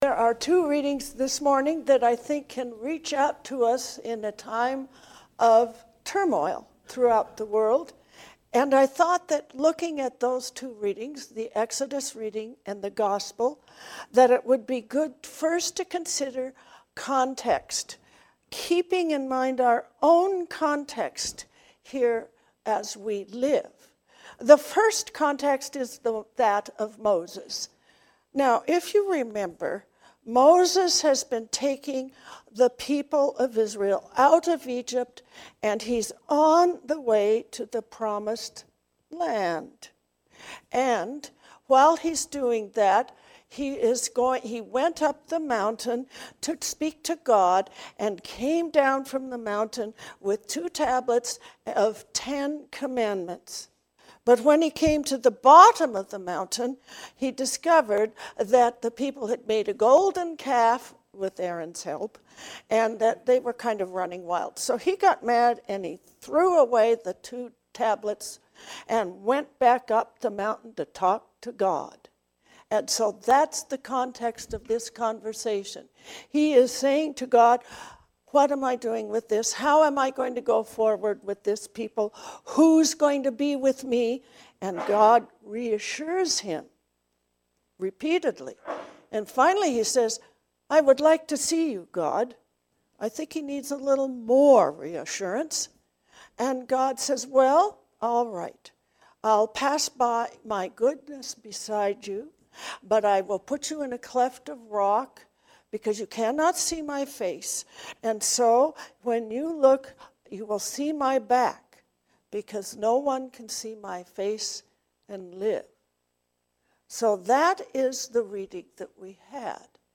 Go to God first. Give to God first. A sermon for the 21st Sunday after Pentecost.